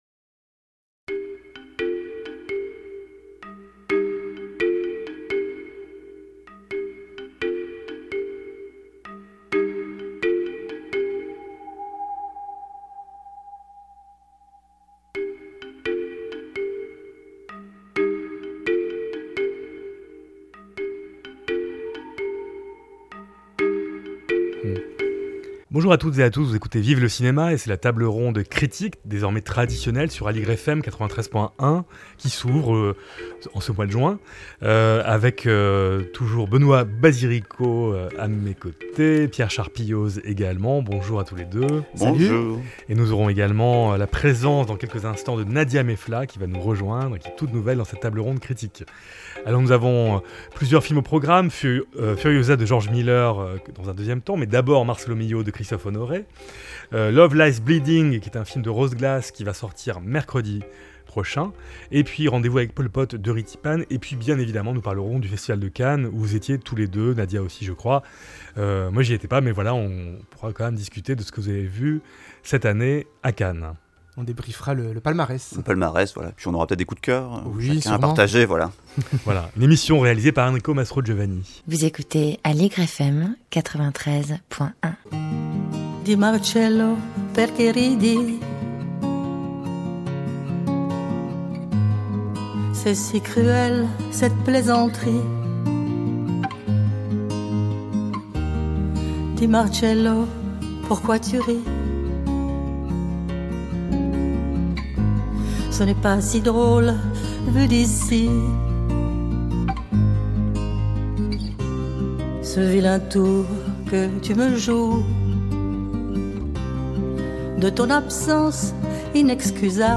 Table ronde critique autour des films :